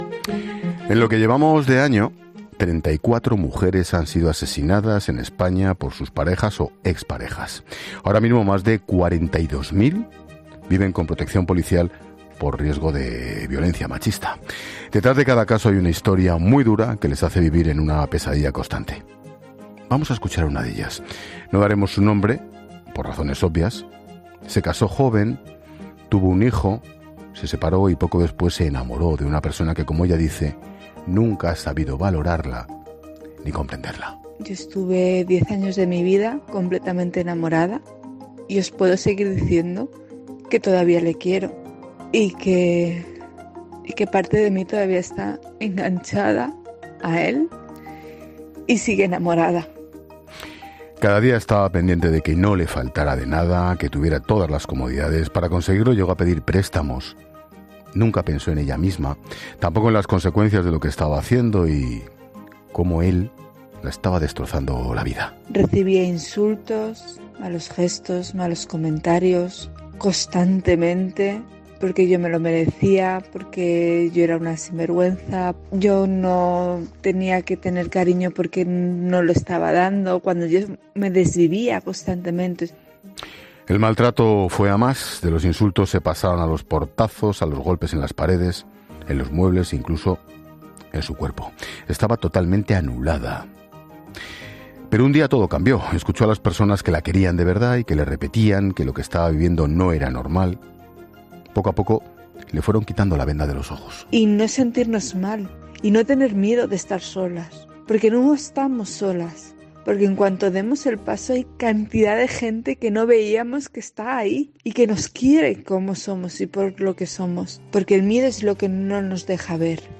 Vamos a escuchar a una de ellas.